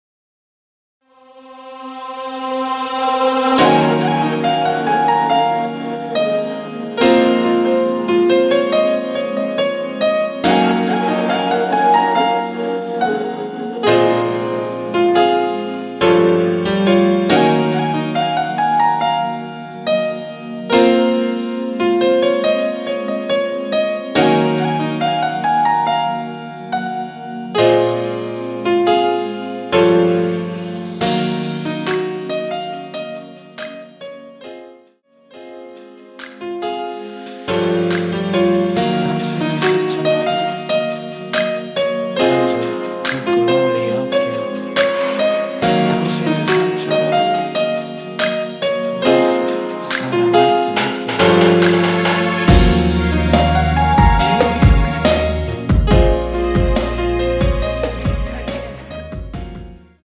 코러스 포함된 MR 입니다.(미리듣기 참조)
Bbm
앞부분30초, 뒷부분30초씩 편집해서 올려 드리고 있습니다.
중간에 음이 끈어지고 다시 나오는 이유는